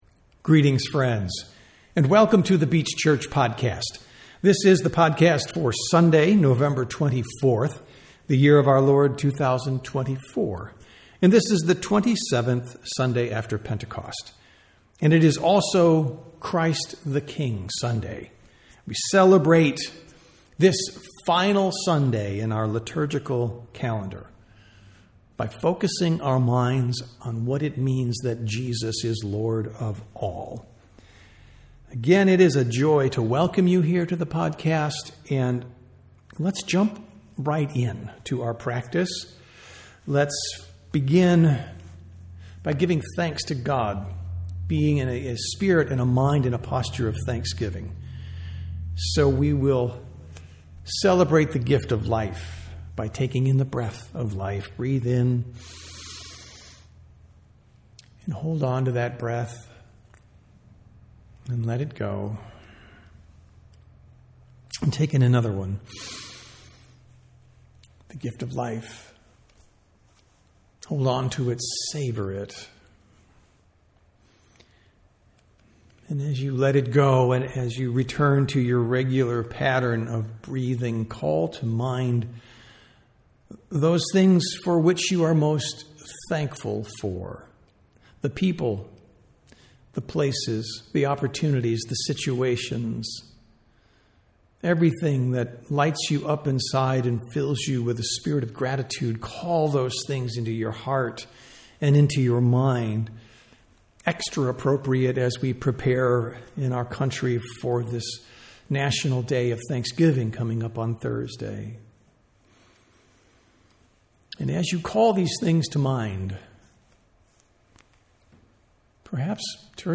Sermons | The Beach Church
Sunday Worship - November 24, 2024